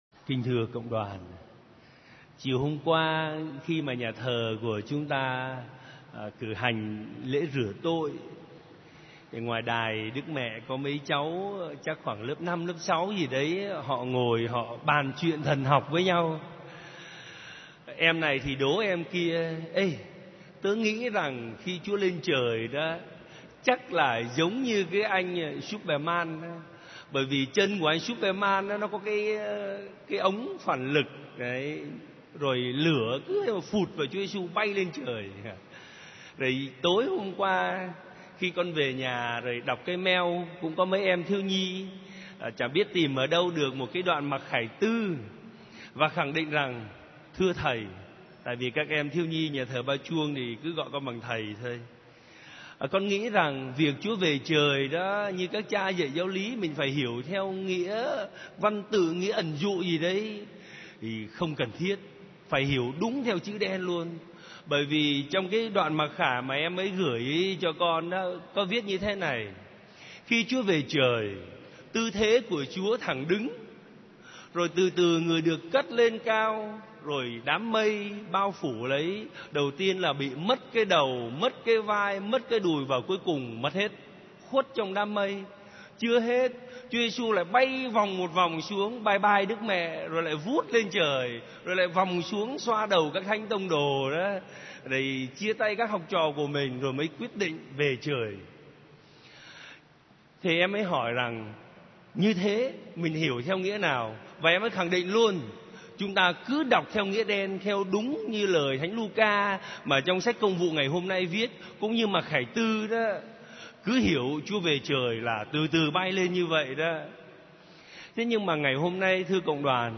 Dòng nhạc : Nghe giảng